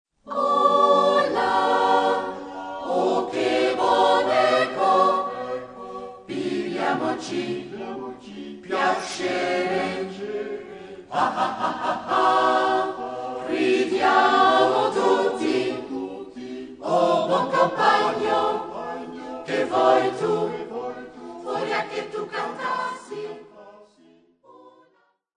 Koor Crescendo Boortmeerbeek